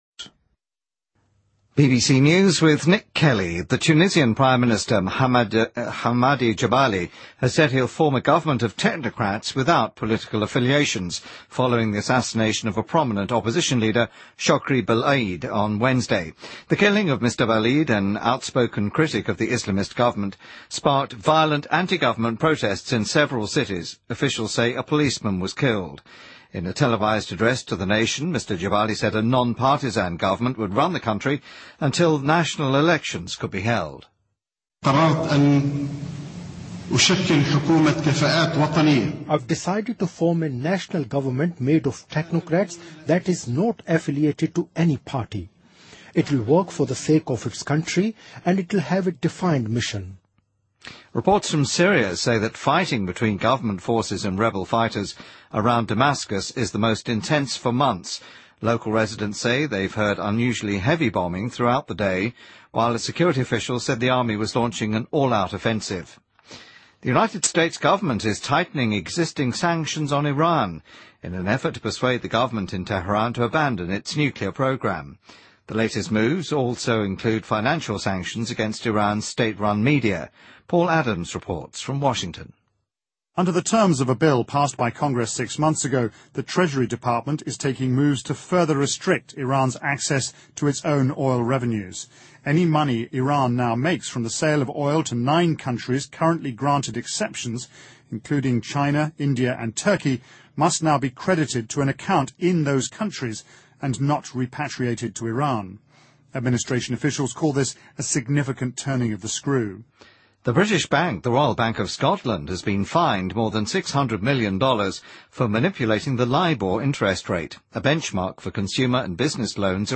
BBC news,2013-02-07